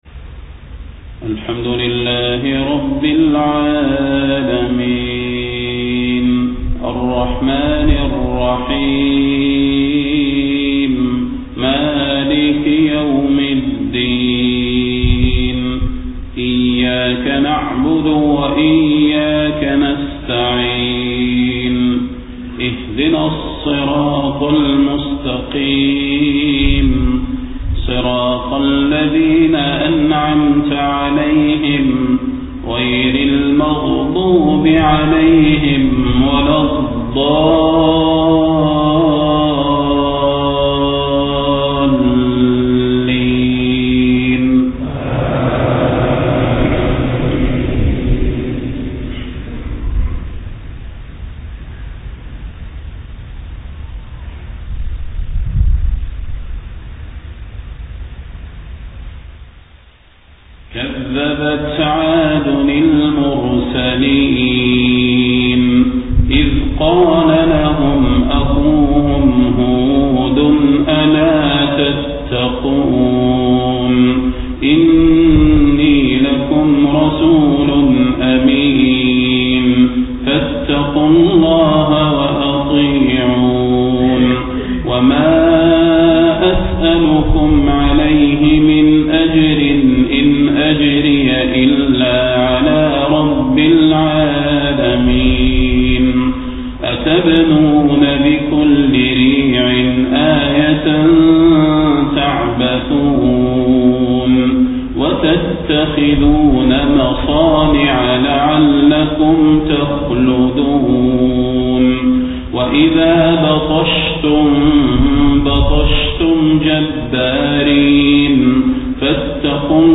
صلاة الفجر 4 ربيع الأول 1431هـ من سورة الشعراء 123-175 > 1431 🕌 > الفروض - تلاوات الحرمين